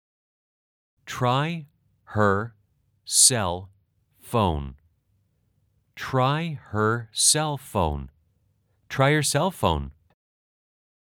/ 츠롸이 허 / 세엘 포온 /